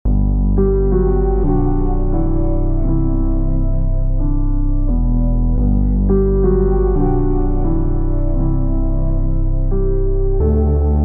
dnb melodic expansion
Celestara Gmin (Full)